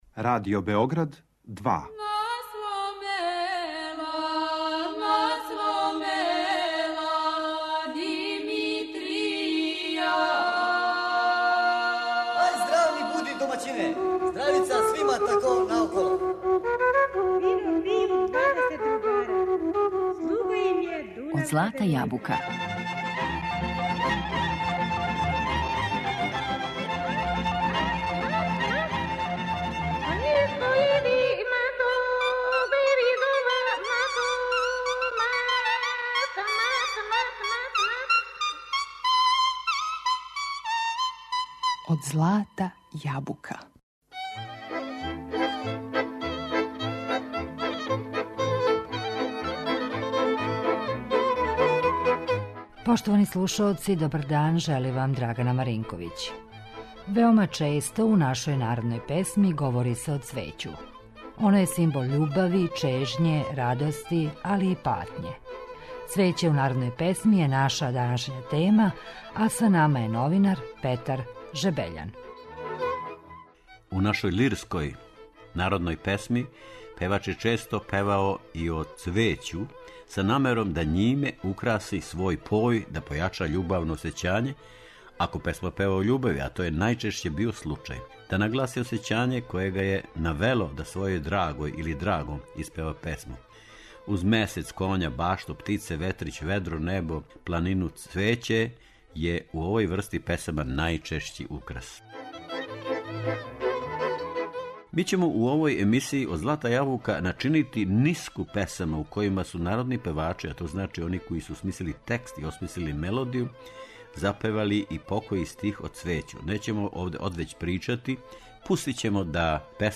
У данашњој емисији начинићемо ниску песама у којима су народни певачи запевали по који стих о цвећу.